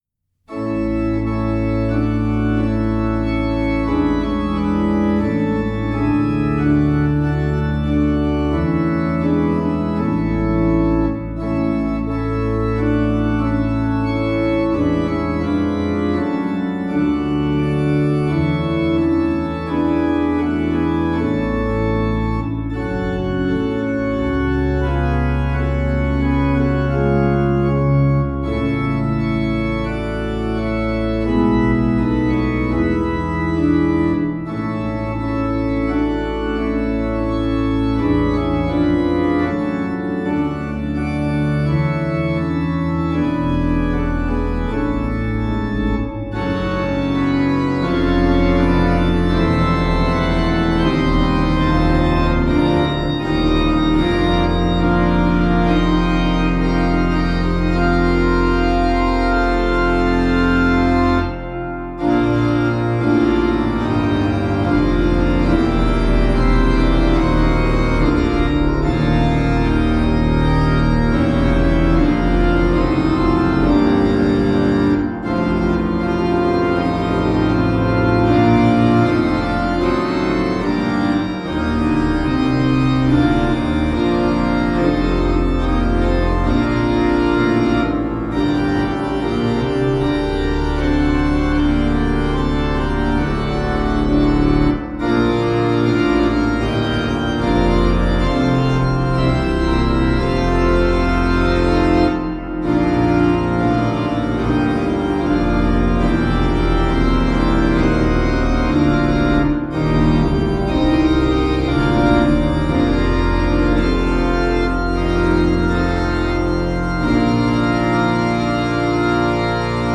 LOVE DIVINE is an appropriate hymn for February. An arrangement, modulation, and story of this hymn is featured in our monthly free sheet music and mp3 download - now available!